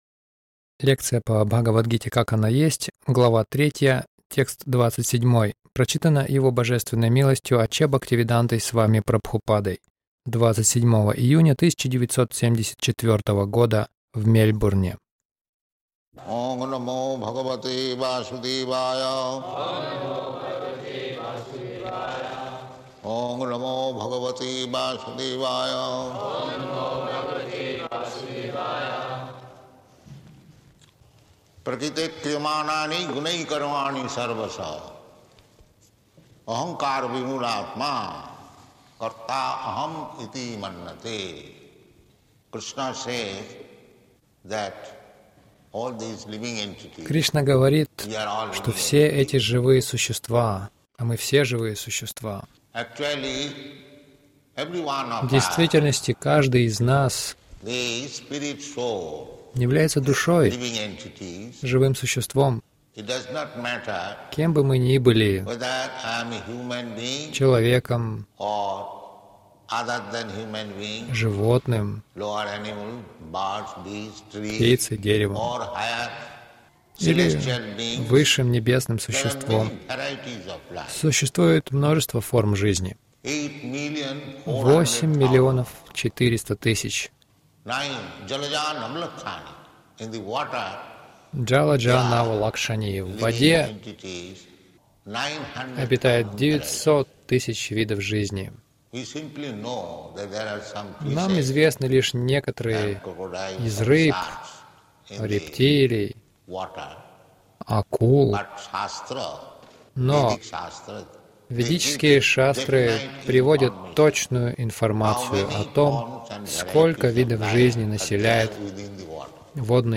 Милость Прабхупады Аудиолекции и книги 27.06.1974 Бхагавад Гита | Мельбурн БГ 03.27 — Проблема счастья в материальном мире Загрузка...